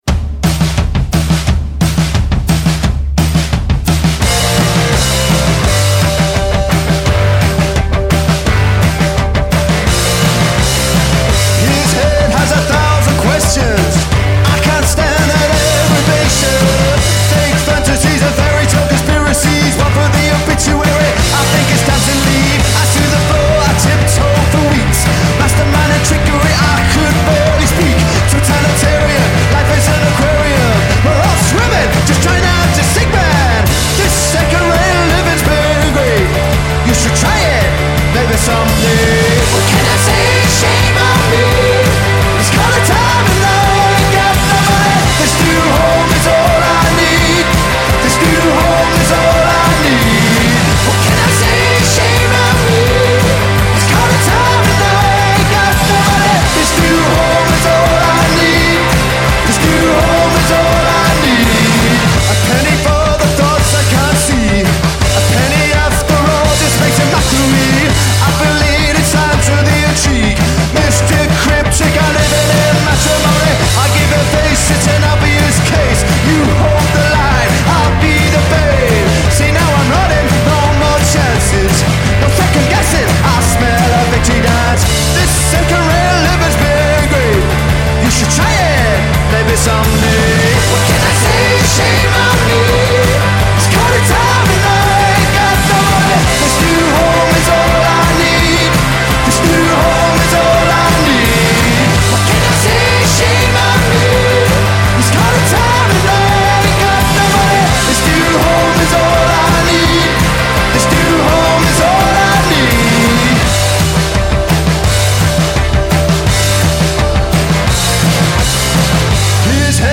Garage rock